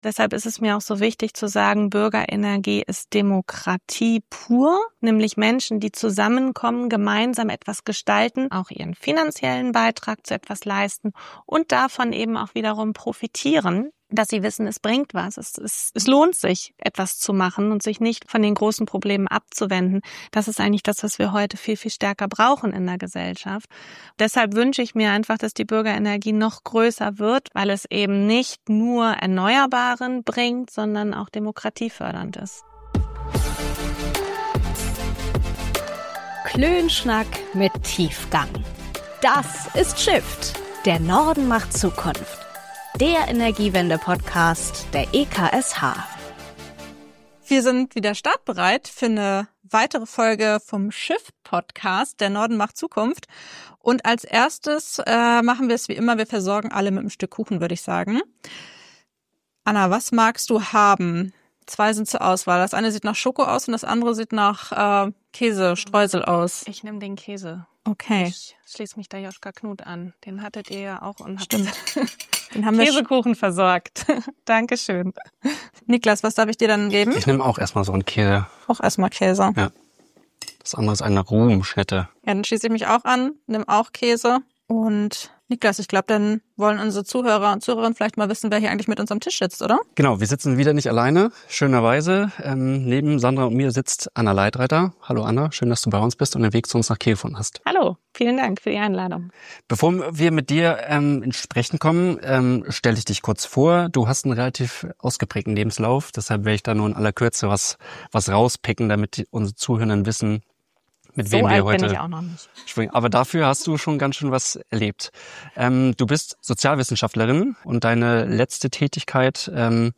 Viel Spaß bei unserem Klönschnack mit Tiefgang!